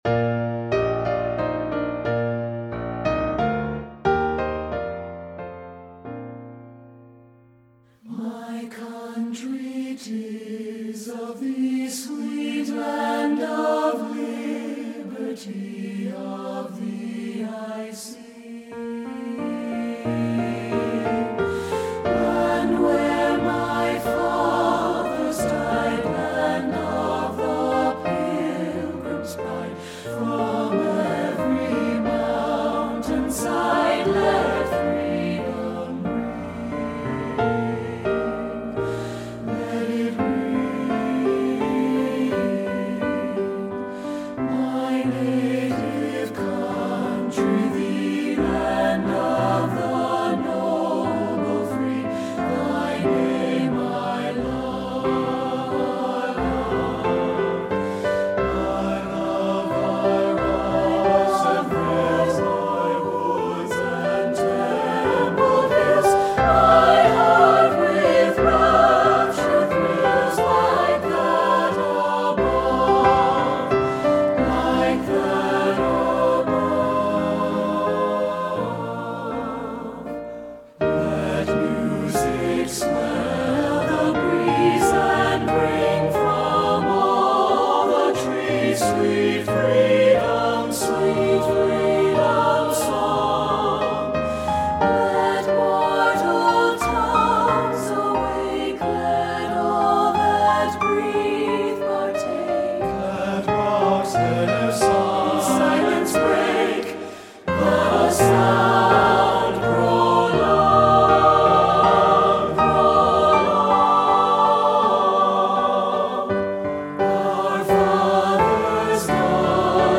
Voicing: SAB and Piano Level